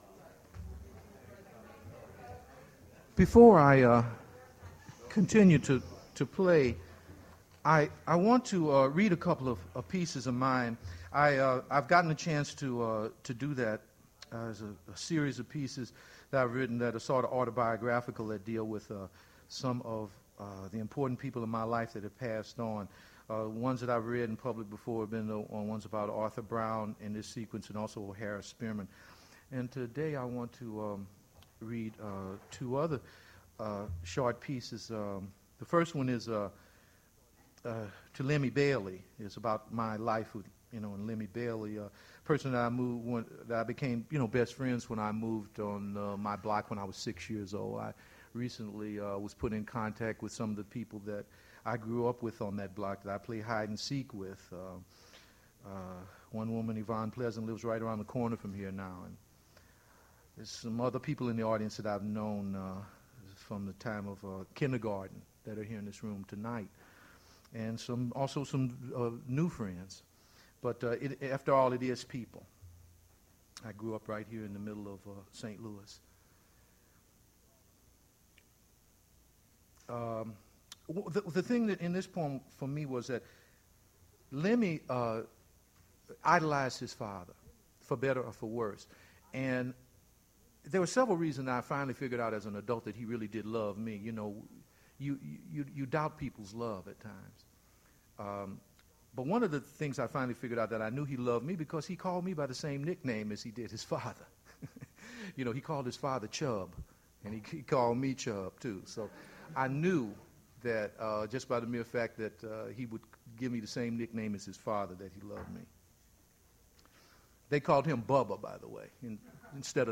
Poetry reading and music
Source mp3 edited access file was created from unedited access file which was sourced from preservation WAV file that was generated from original audio cassette.
opening musical piece and other musical interludes have been excised